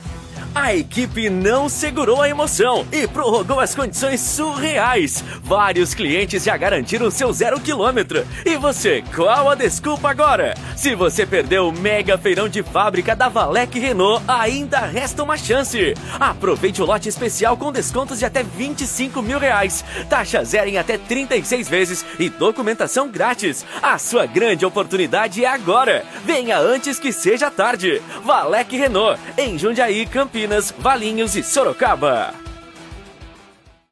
ANIMADO 2: